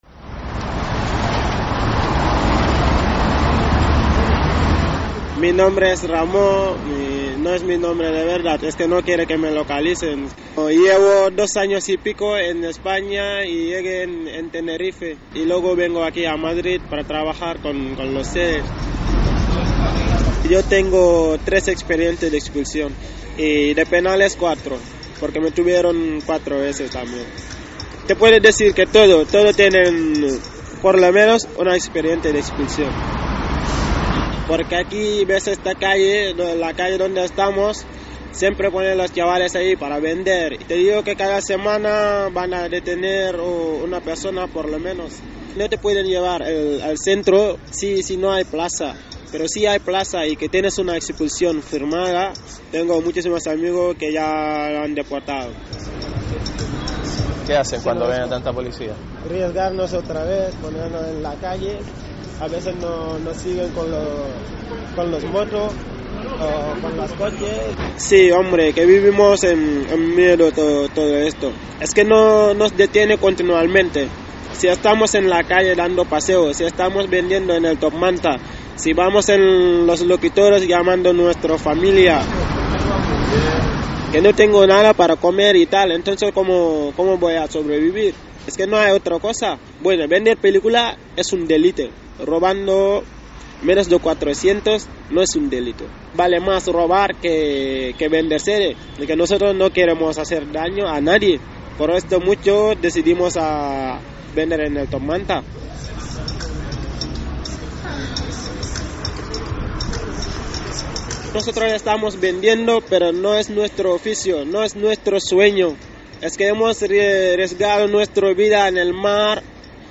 Reportatge sobre els "manters" i la seva situació personal i legal
Informatiu